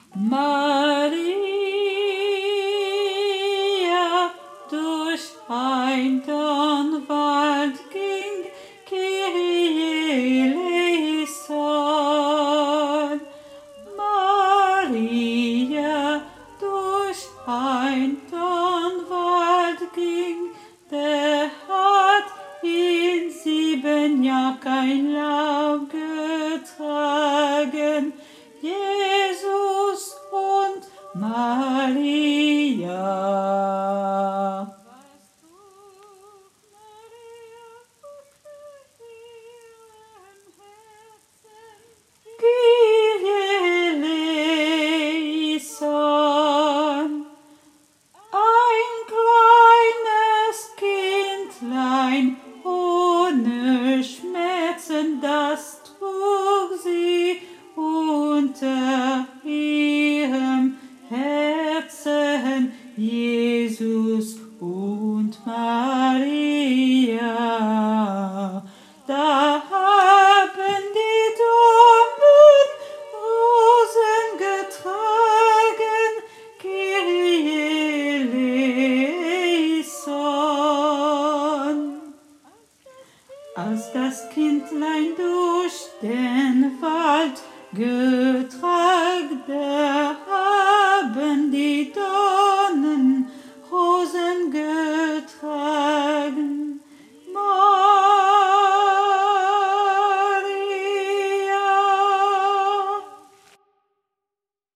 Basse
MP3 rendu voix synth.